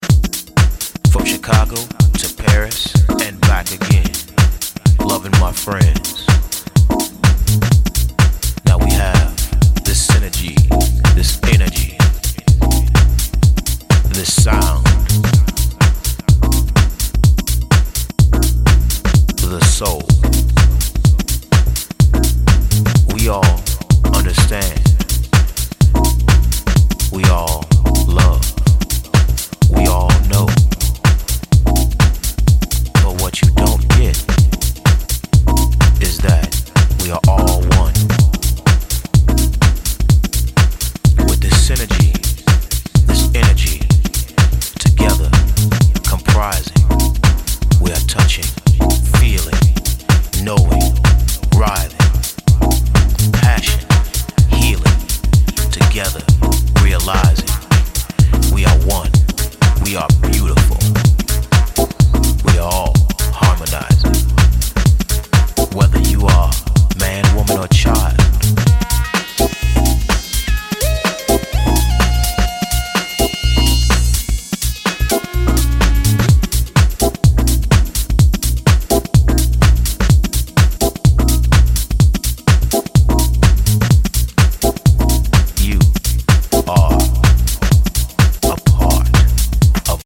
deep house
soulful vocals, jazzy harmonies, funky bass lines